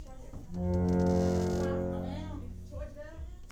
The organ can be zipped